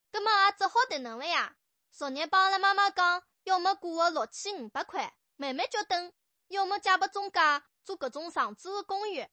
描述：风风火火的沪上阿姐。
支持的语种/方言：中文（吴语）